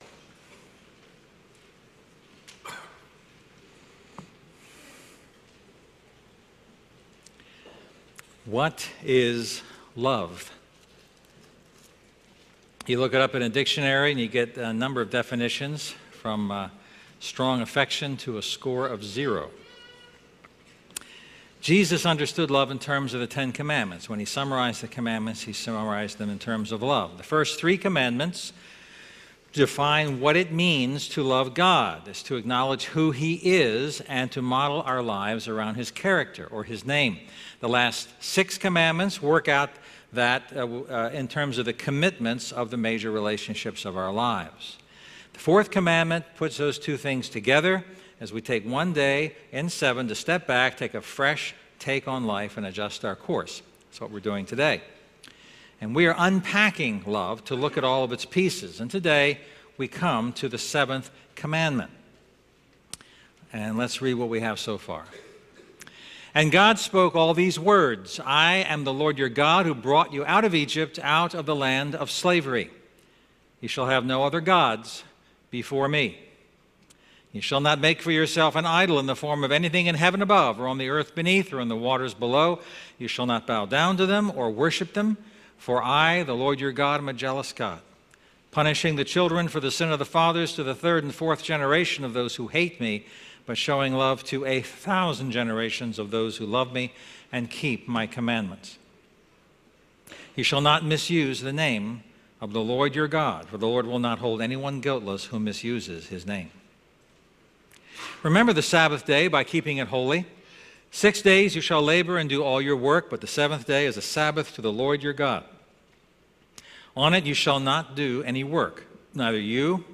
Sermons – GrowthGround